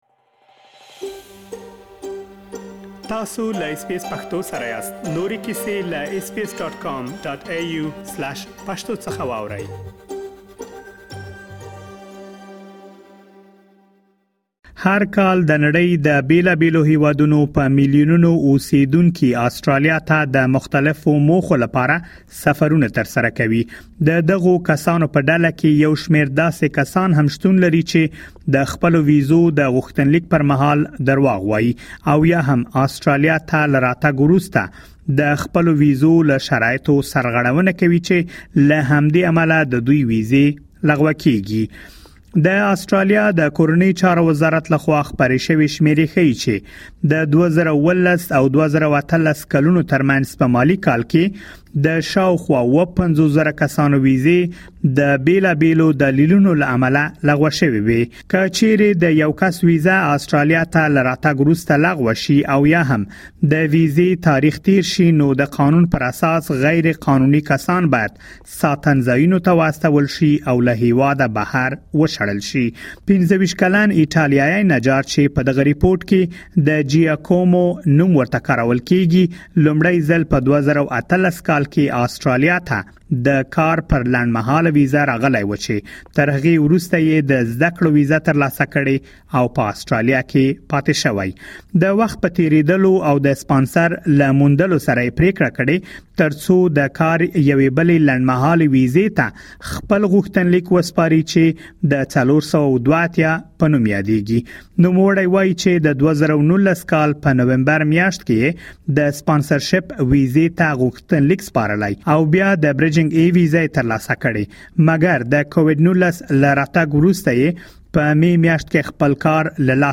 هر کال د نړۍ د بېلابېلو هېوادونو په میلیونونو اوسېدونکي آسټرالیا ته د مختلفو موخو لپاره سفرونه ترسره کوي. له بهر څخه اسټراليا ته راغلي کسان د اسټراليايي ويزو برخه کې له ځينو ستونزو سره مخ کيږي چې پدې رپوټ کي مو دا ټول اړخونه تاسو ته راخيستي.